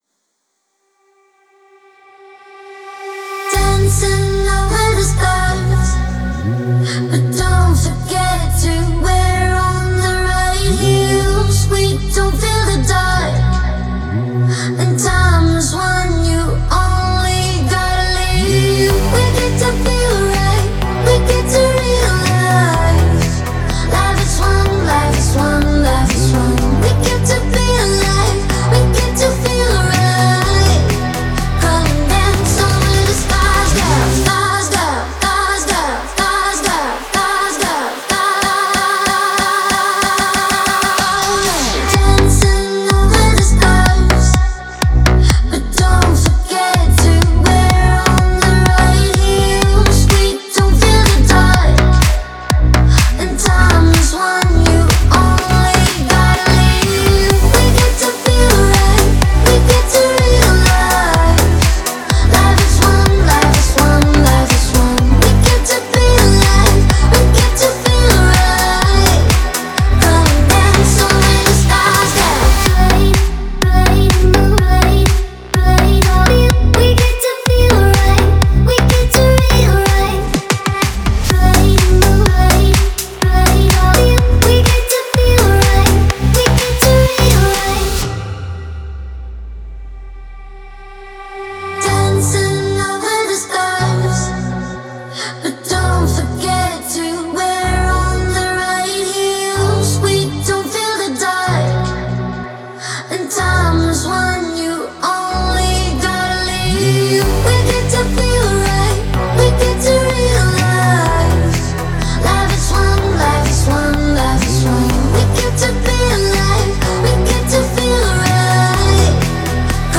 это энергичная композиция в жанре электронной музыки